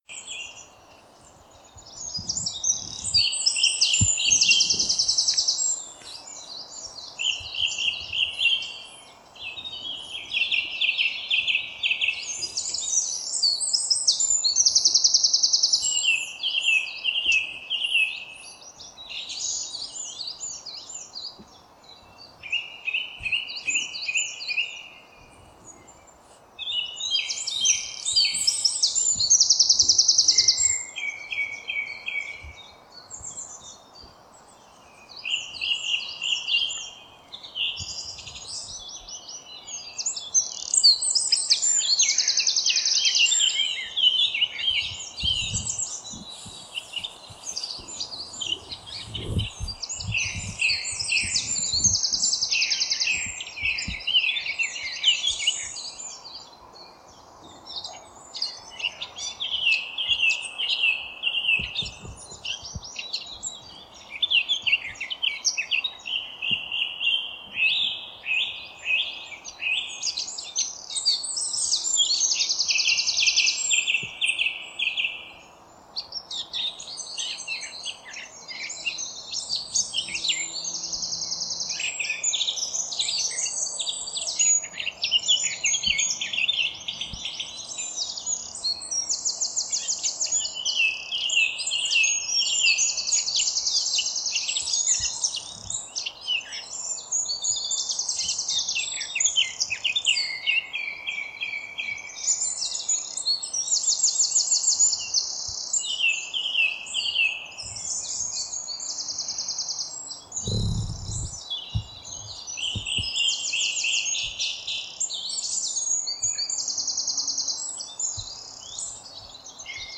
Dawn Chorus
Sunday morning, stupidly early, so I assumed there would be no noise from the motorway to mess things up.
In this one, you hear wrens and a song thrush, and after about 45 secs a blackcap starts to join in for a while.
Try to listen in stereo as it gives much more depth – a wren is on the left and the thrush and blackcap on the right.
dawn-chorus-3.mp3